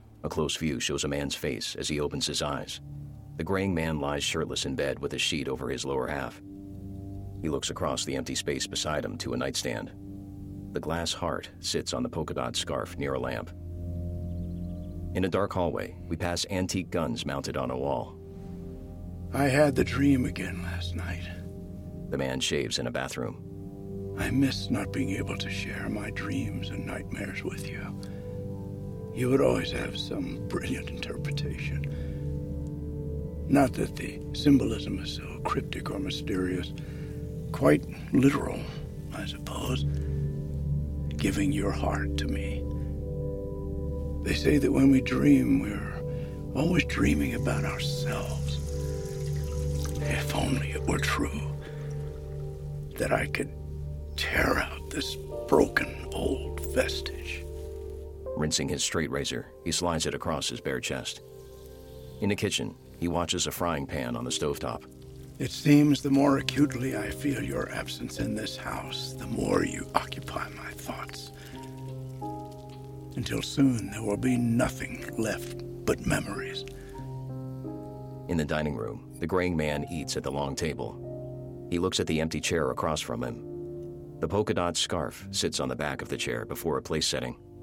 audio description, short film, vo samples
Heartsick-AD-sample.mp3